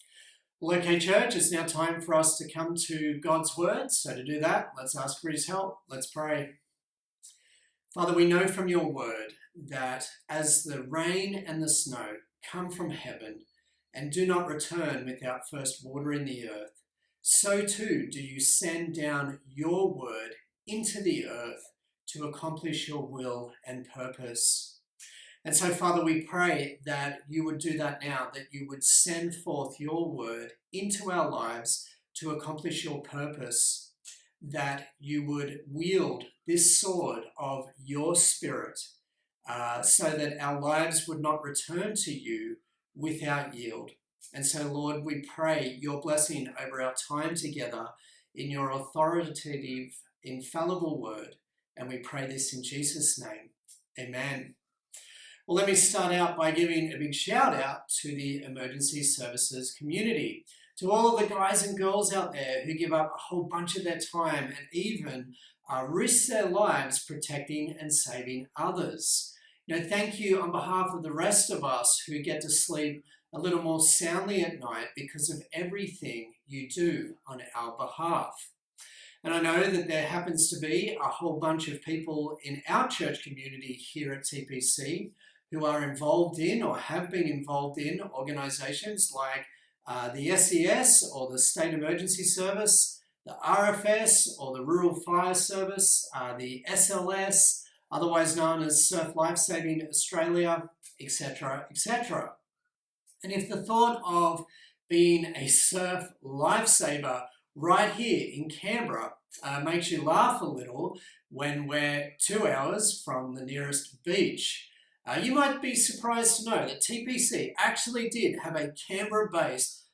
Acts Passage: Acts 16:11-40 Service Type: Sunday Morning